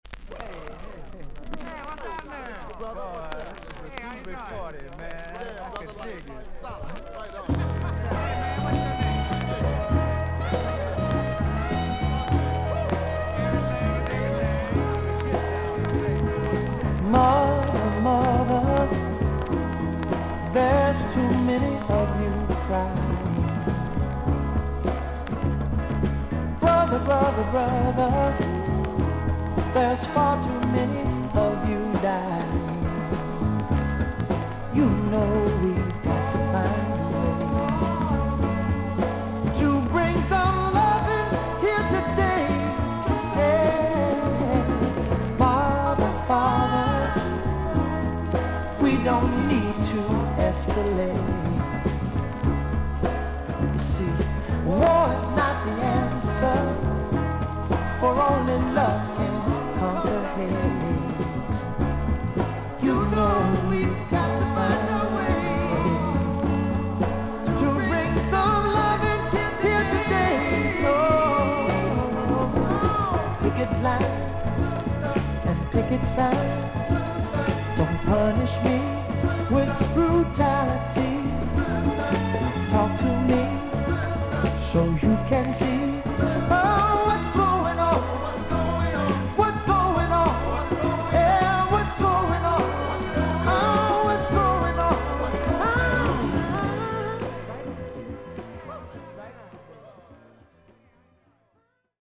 所々に軽い周回ノイズ、少々パチノイズの箇所あり。全体的に大きめのサーフィス・ノイズあり。
R&B/ソウル・シンガー。